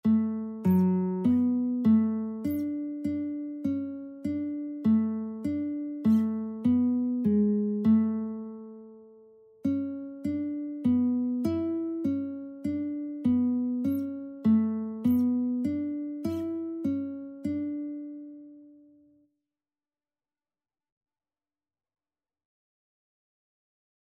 Christian Christian Lead Sheets Sheet Music O God, Our Help in Ages Past (St. Anne)
D major (Sounding Pitch) (View more D major Music for Lead Sheets )
4/4 (View more 4/4 Music)
Classical (View more Classical Lead Sheets Music)